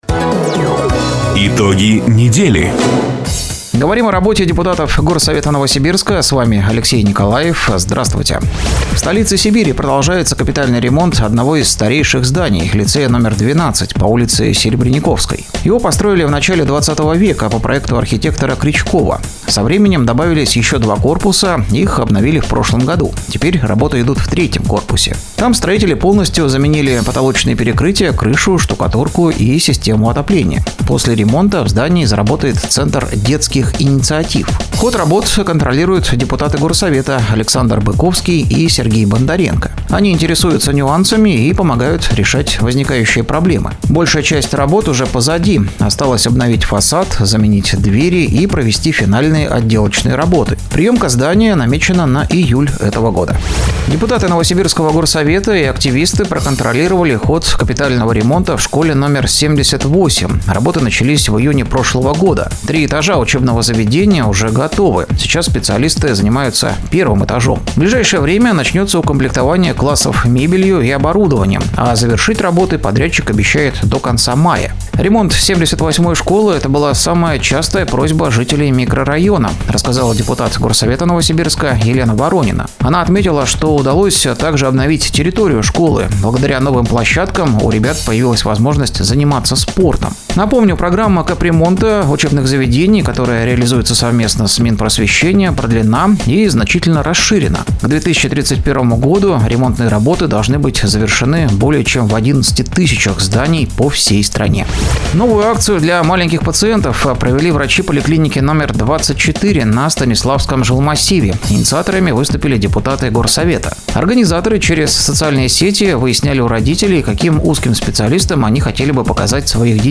Запись программы "Итоги недели", транслированной радио "Дача" 05 апреля 2025 года